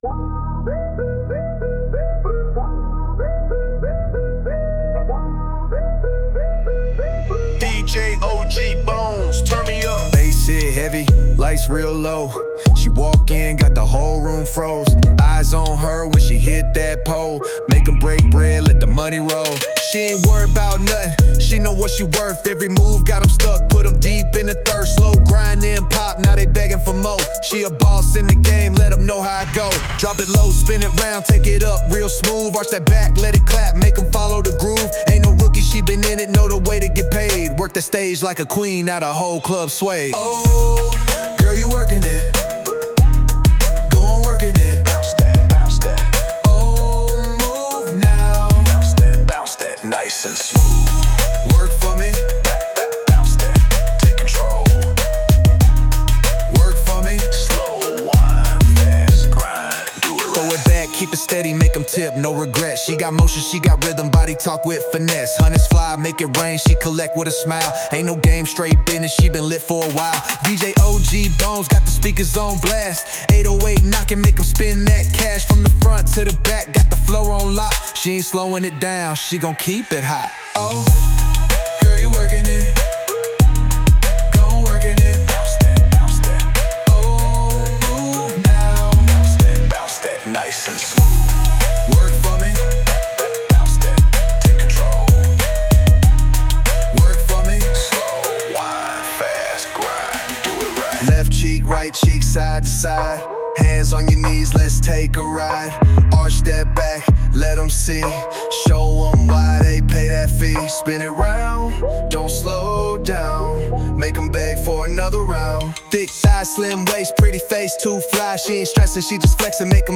Hiphop
Ain't no slowing down - this one GOES HARD!